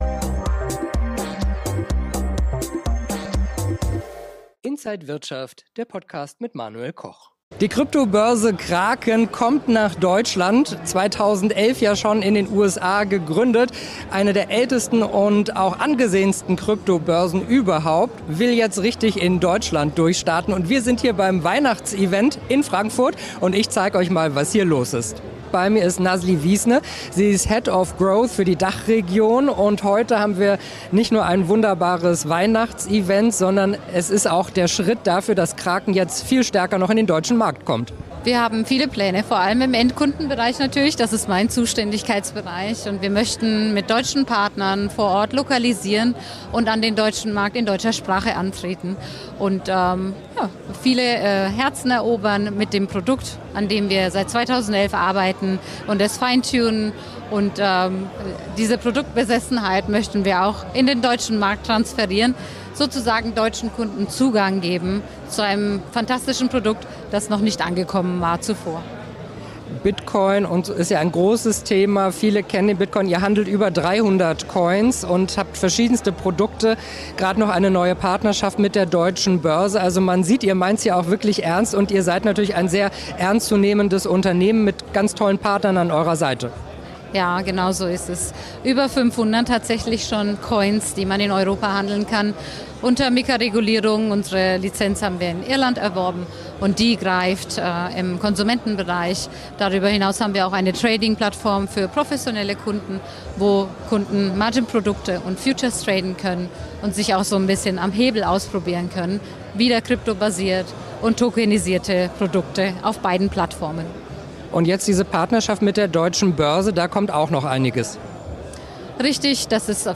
Krypto-Währung Kraken mit großem Event in Frankfurt